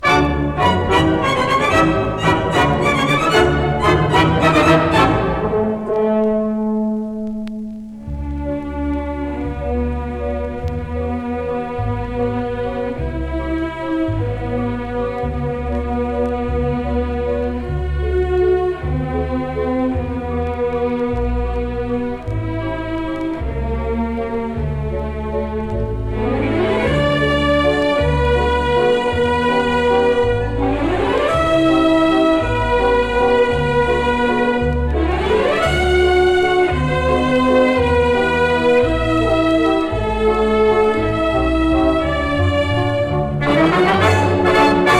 Jazz, Easy Listening, Lounge　USA　12inchレコード　33rpm　Stereo